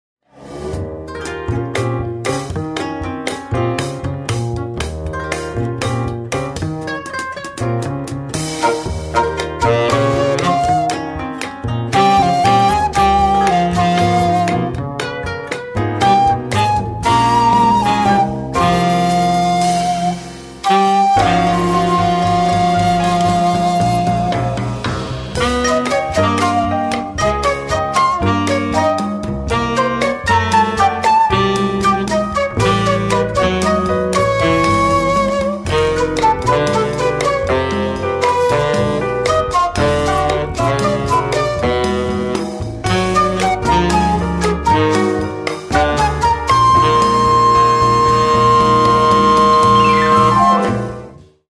A studio recording of this intercultural ensemble
shakuhachi
koto
saxophones
bass
drums